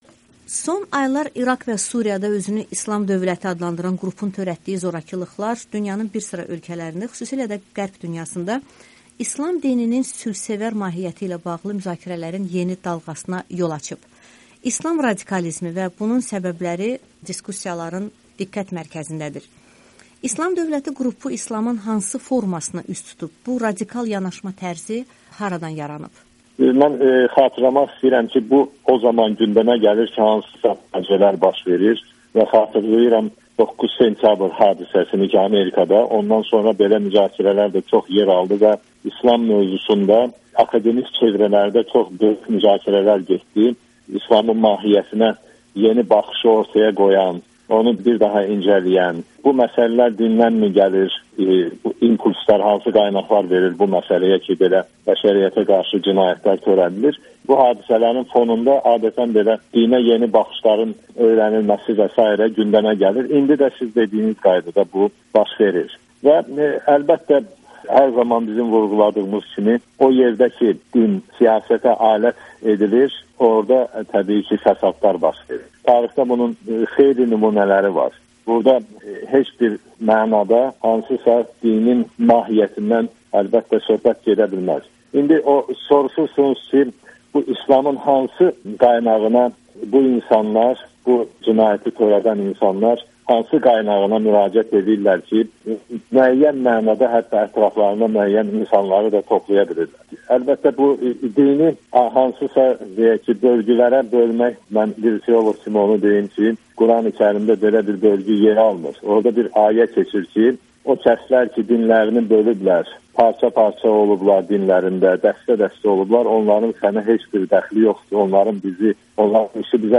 İlahiyyatçı alim Amerikanın Səsinə müsahibə siyasi islam, dinin mahiyyəti, Qurani-Kərimdə qadına münasibət və Azərbaycanda radikalizmə müqavimətlə bağlı fikirlərini bölüşüb